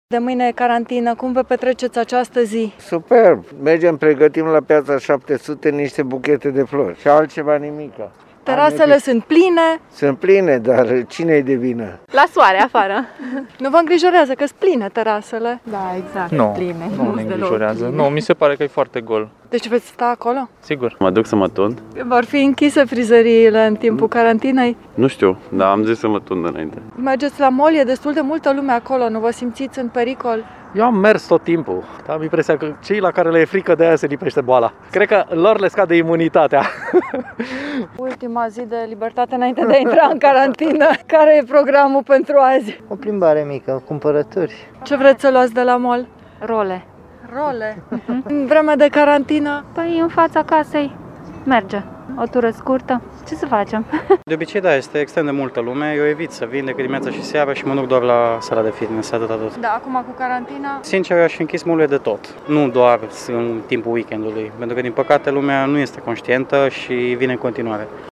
Vox-atmosfera-azi.mp3